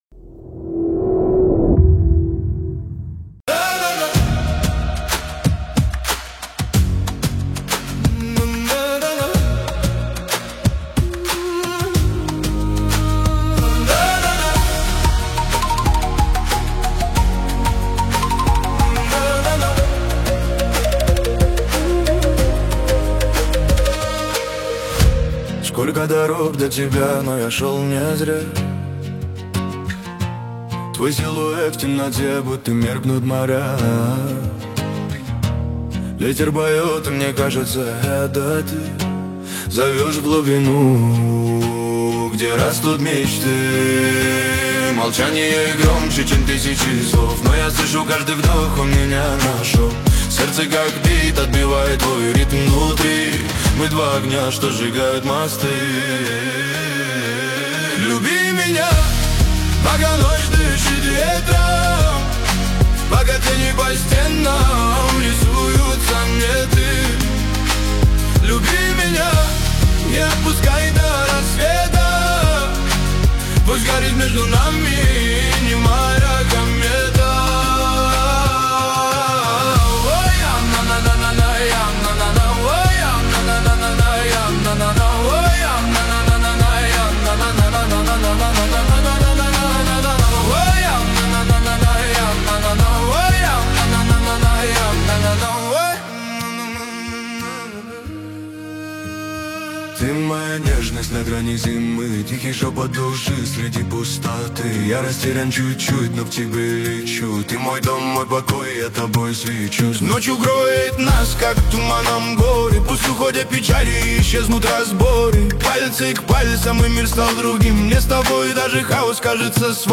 Музыка / 2026-год / Клубная / Рэп / Поп